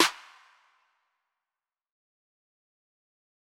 JJSnares (5).wav